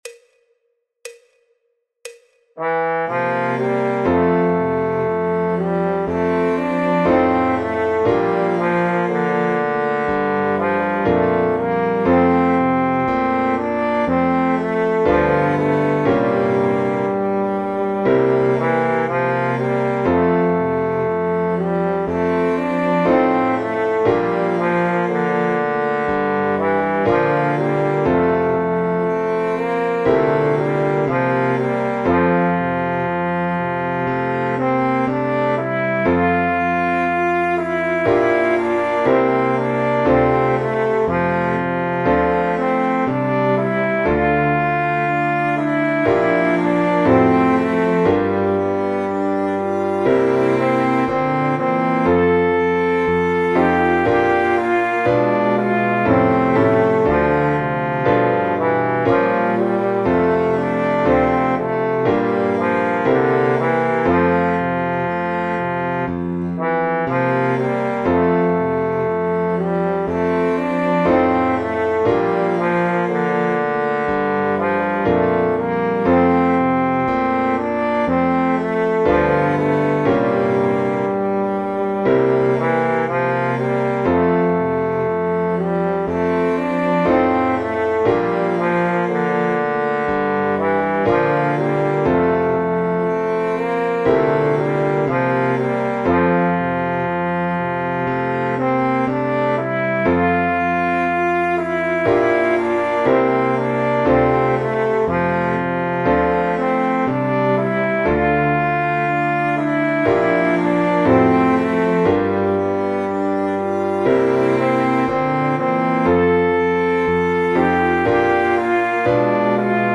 El MIDI tiene la base instrumental de acompañamiento.